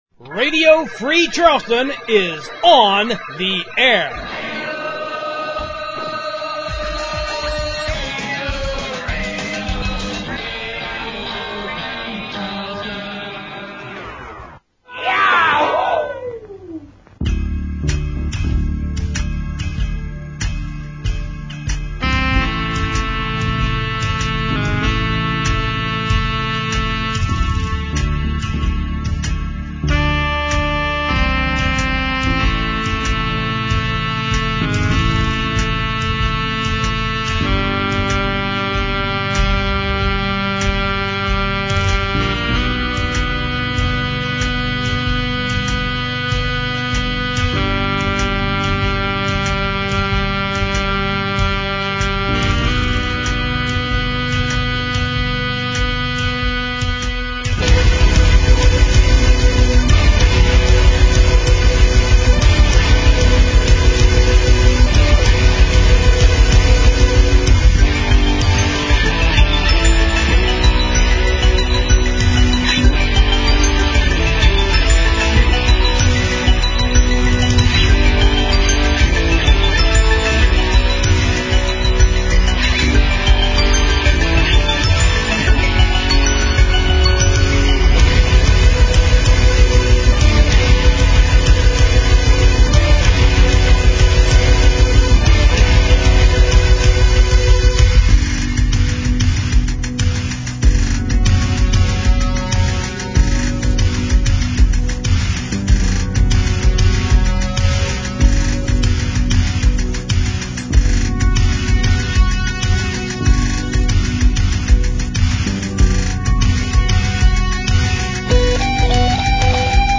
Making things even better is the fact that both shows were recorded Monday despite constant interruptions, loud wind, and heavy traffic going right through my yard.
I sound like I’m talking a mile a minute to squeeze in the announcing between catastrophes, but I’m trying to be optimistic here.